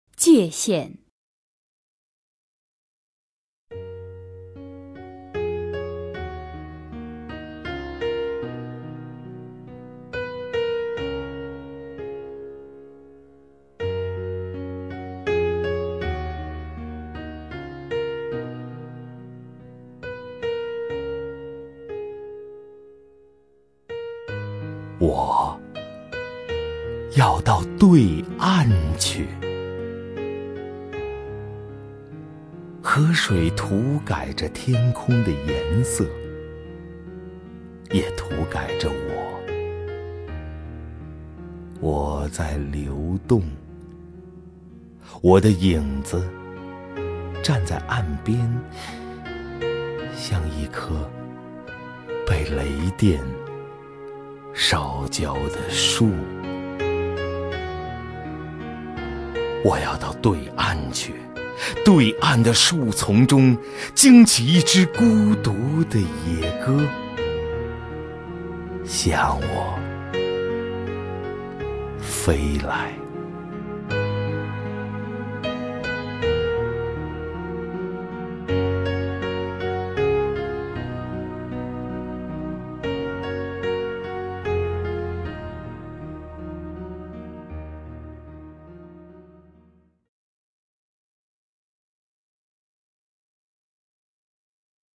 首页 视听 名家朗诵欣赏 赵屹鸥
赵屹鸥朗诵：《界限》(北岛)　/ 北岛